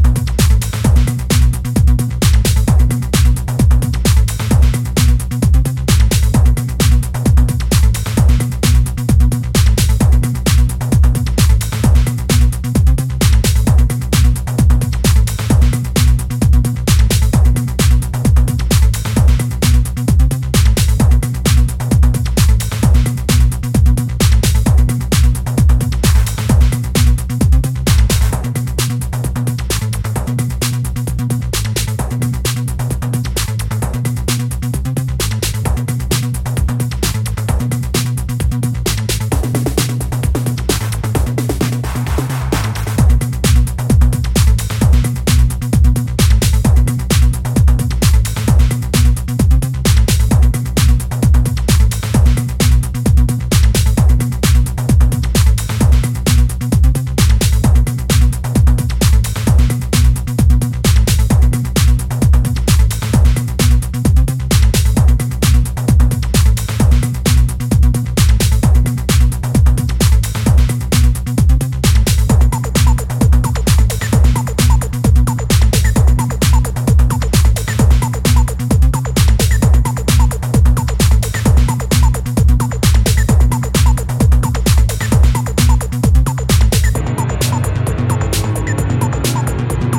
EBMをミニマル化しピークタイムを持続させる
全体的に意識されていそうなミニマルな展開が引力を高めています。